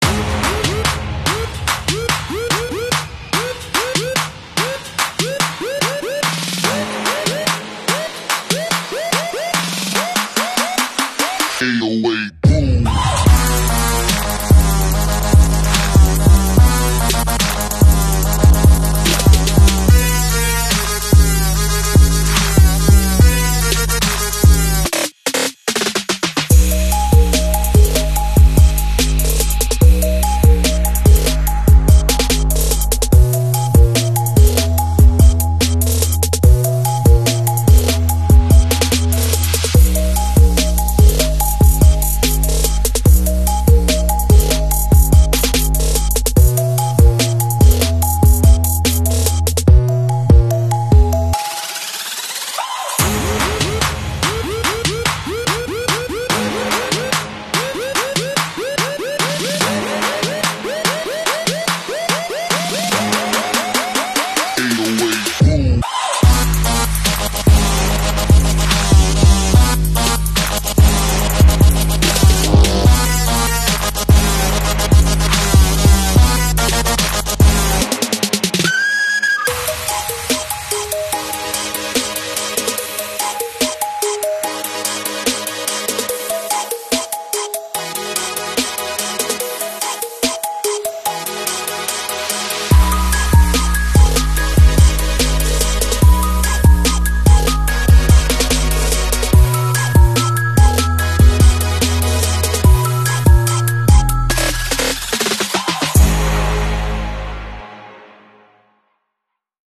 🔥 Downpipe para Honda Civic sound effects free download
✅ Downpipe de alto flujo – Mayor potencia y respuesta más rápida del turbo. ✅ Sonido más deportivo y agresivo, sin ser invasivo.